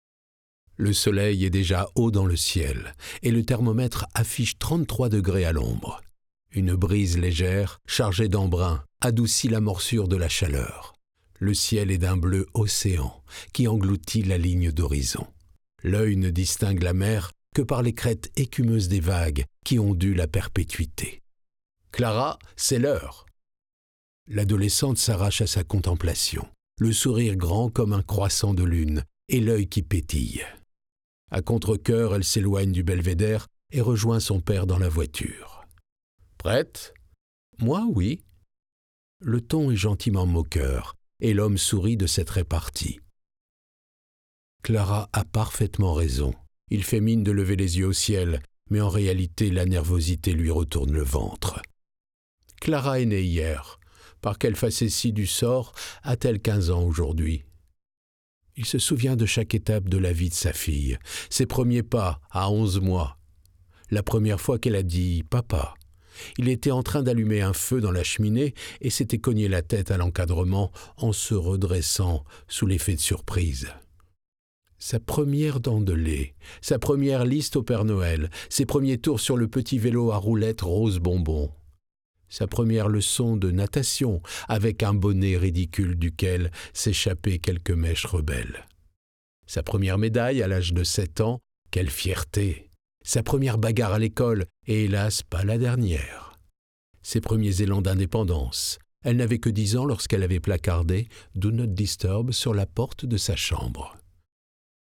LIVRE AUDIO ROMAN
30 - 45 ans - Baryton Ténor